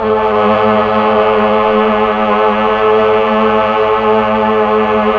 Index of /90_sSampleCDs/Roland LCDP09 Keys of the 60s and 70s 1/PAD_Melo.Str+Vox/PAD_Tron Str+Vox